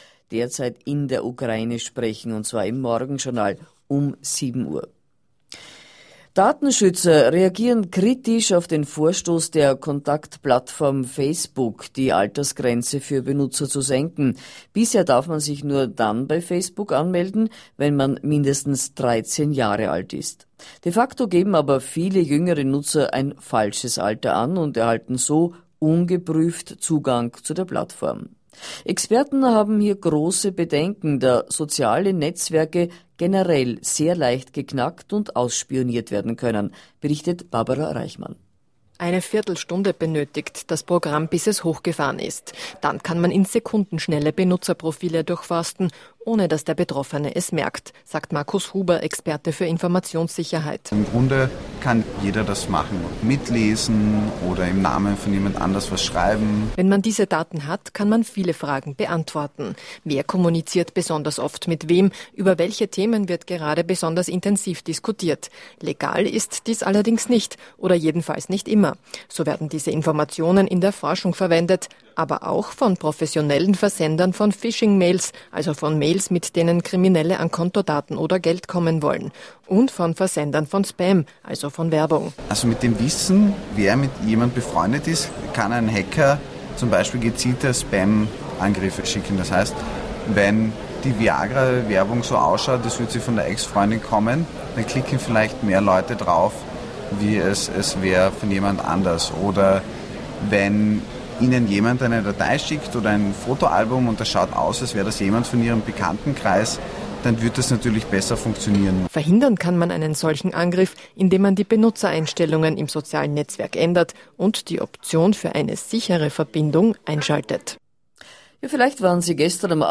An interview concerning social network security. Ö1 aired the interview in their daily journals on June 8th 2012.